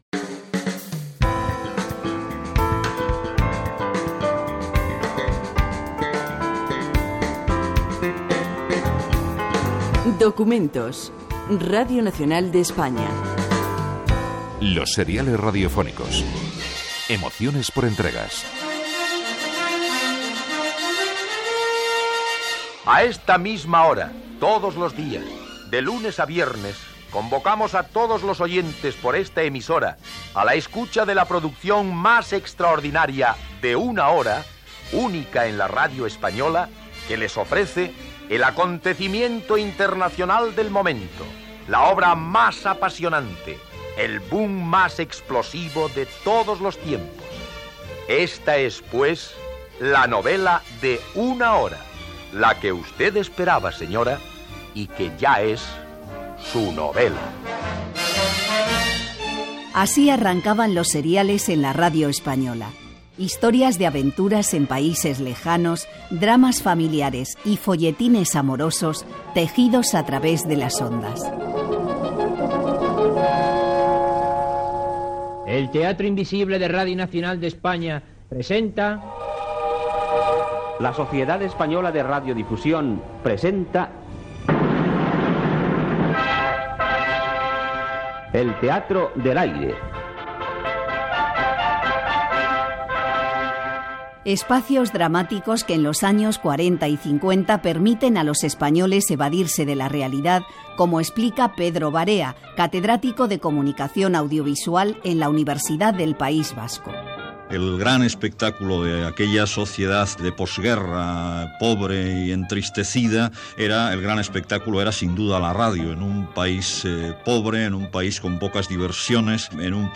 Careta del programa
Gènere radiofònic Divulgació